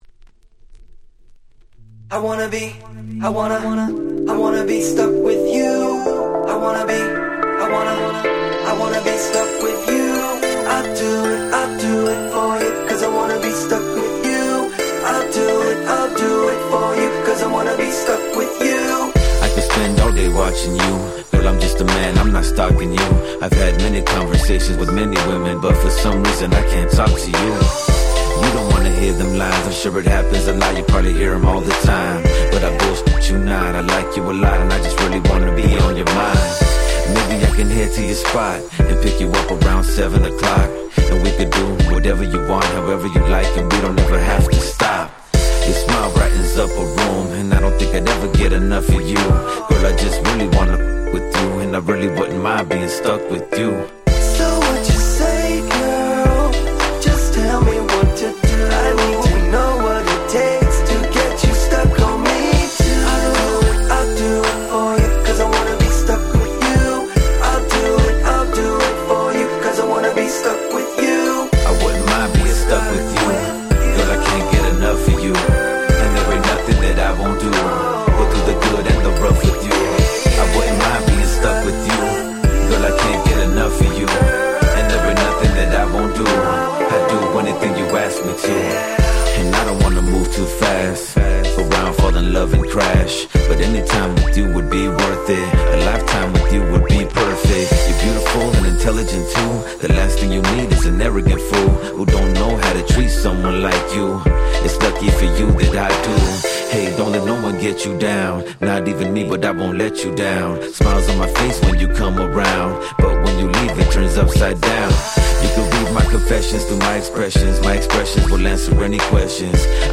07' Very Nice Chicano Rap !!
MellowでGroovyな日本人受けバッチリな1曲！
West Coast Hip Hop